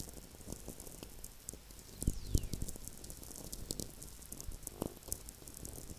Cumiana, NW Italy